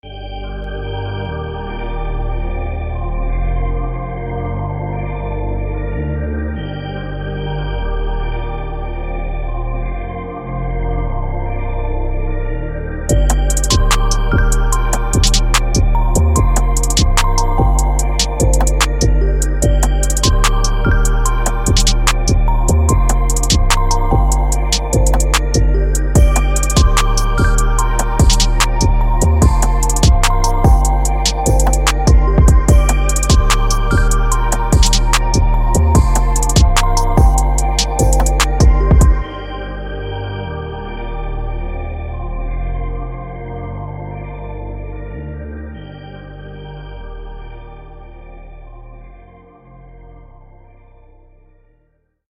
Hip HopTrap
•包括所有节奏
•20个旋律循环